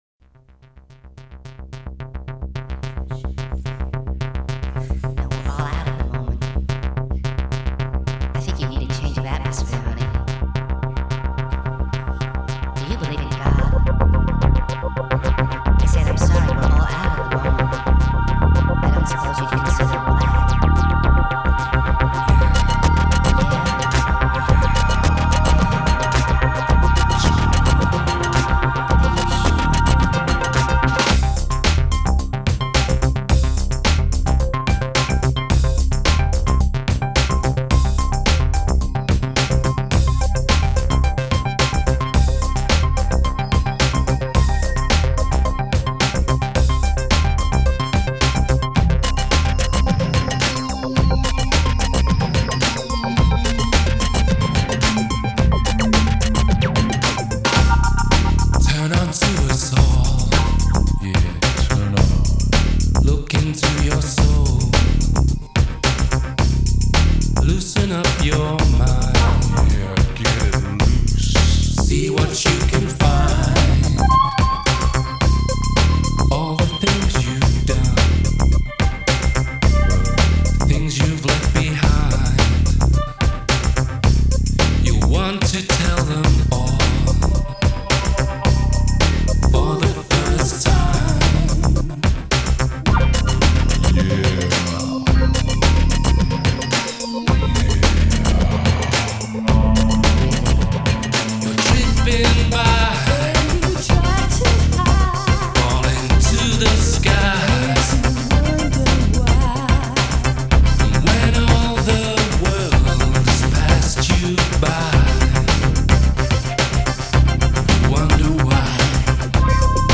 Kraftwerk-inspired rippling rhythm